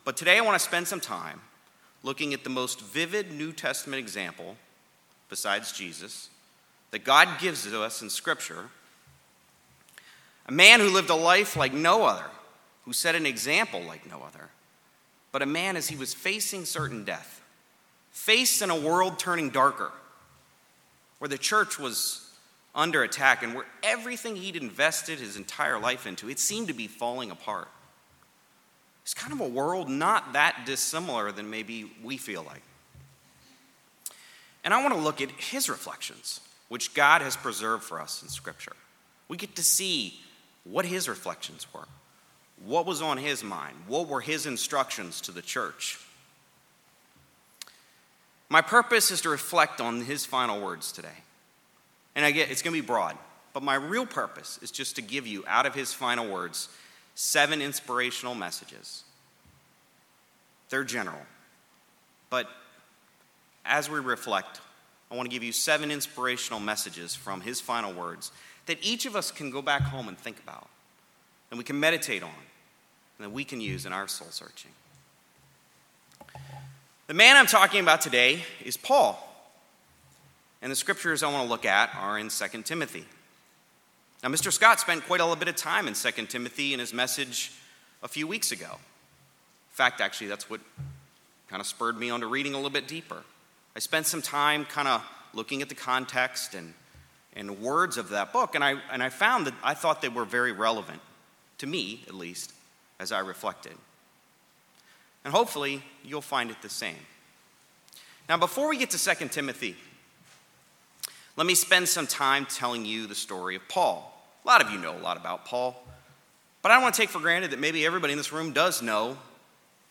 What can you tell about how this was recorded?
Given in Greensboro, NC Jacksonville, NC Raleigh, NC